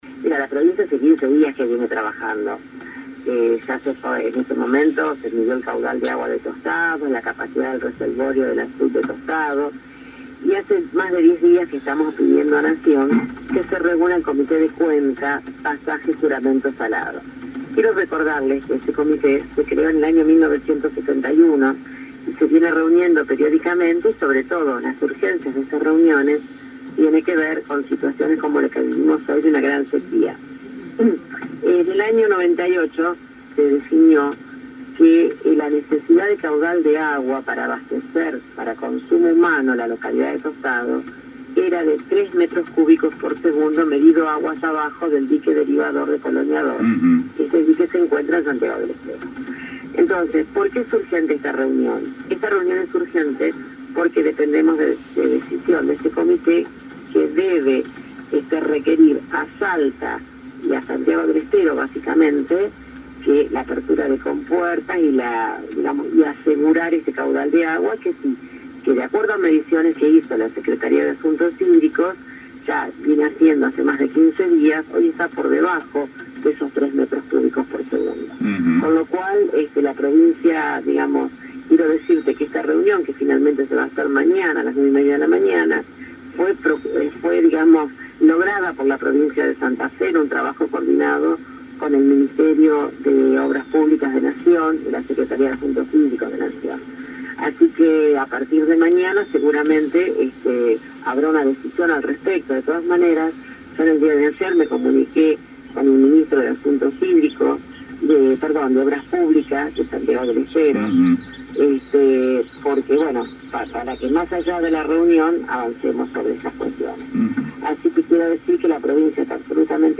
Por su parte, este martes la ministra de Infraestructura, Servicios Públicos y Hábitat de Santa Fe, Silvina Frana, señaló que desde hace días el gobierno provincial viene siguiendo de cerca el tema, solicitando además a Nación que se reúna el Comité de Cuenca Pasaje Juramento – Salado, lo que finalmente se concretará este miércoles a la mañana.
Silvina-Frana-agua-Tostado-1.mp3